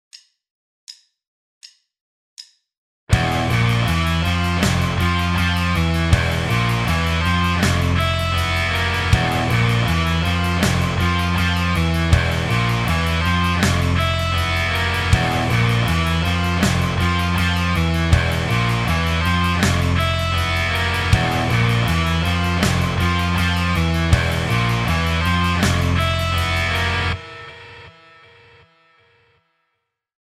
这里的2级音是F，5级音是降B，根音是E。注意练习最后3个音特别不和谐。